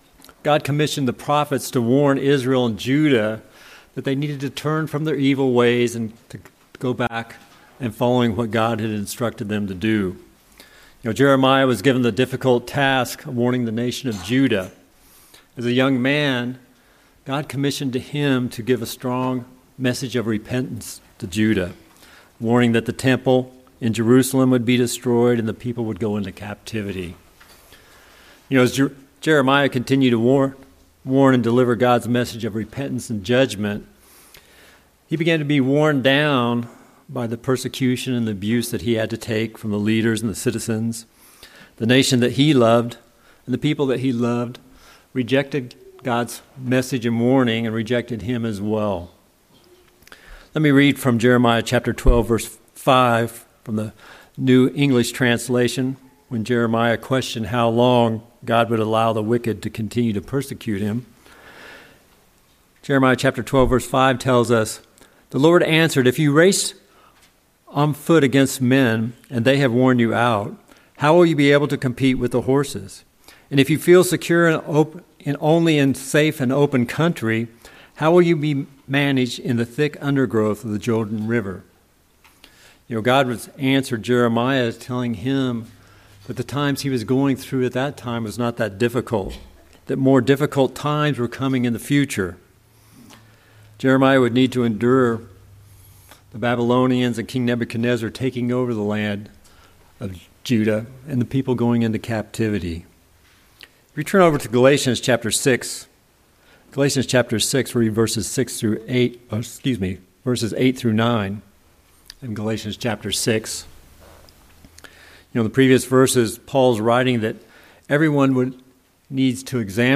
In this sermon we'll discuss how to avoid and overcome spiritual burnout in our lives.